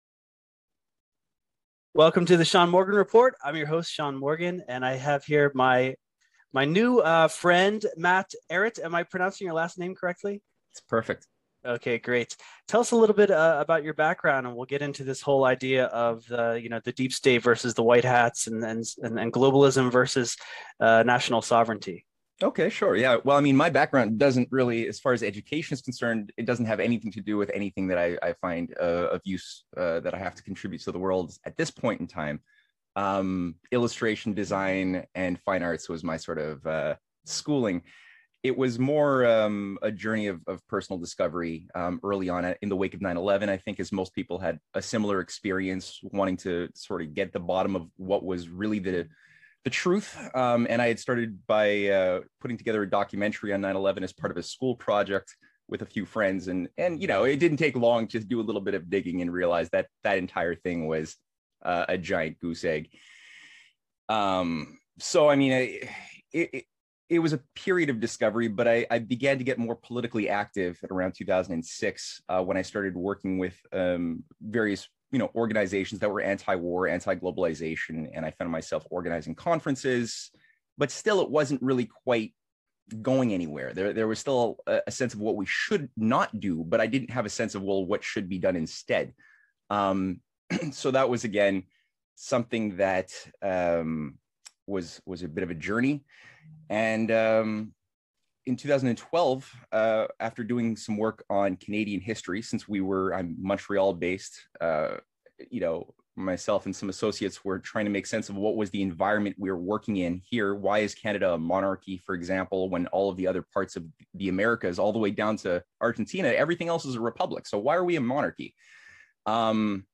Who is the Deep State_ A Conversation